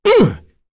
Roi-Vox_Attack3.wav